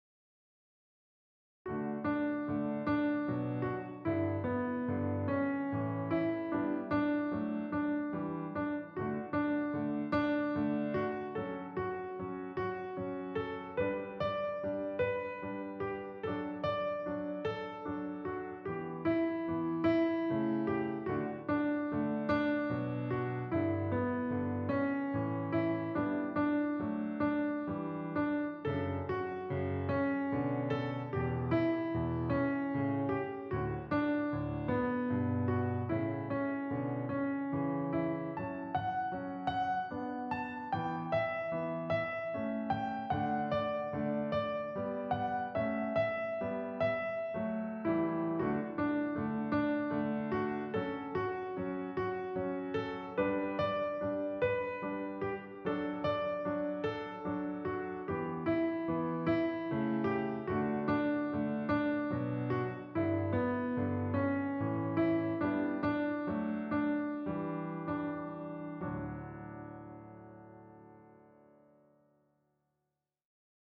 Twinklepated is a syncopated variation of Twinkle Twinkle Little Star.
This Twinkle etude – Twinklepated is set in 3/4 time matching the time signature of the Boccherini Minuet.
Twinklepated – D Major Accompaniment Track